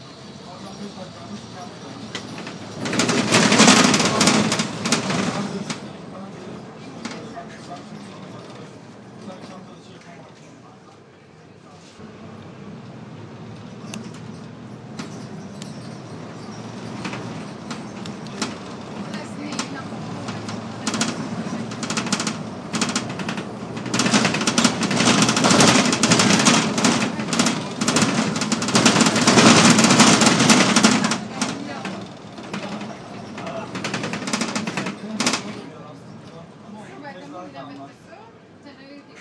Loud juddering bus